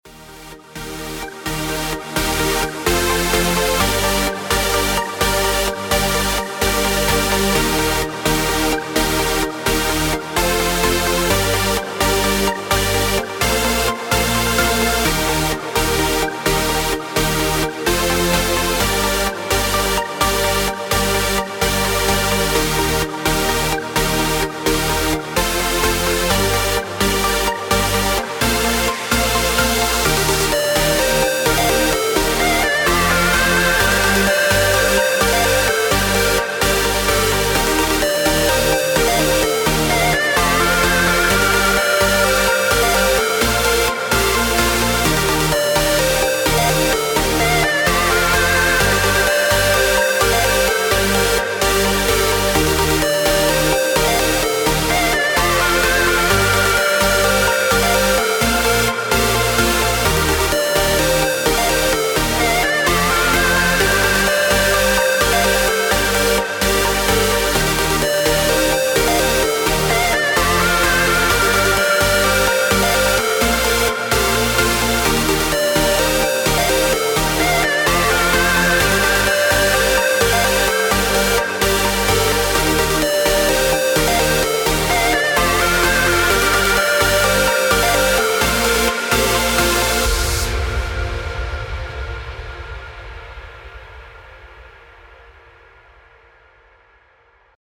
Ideal for Trance - House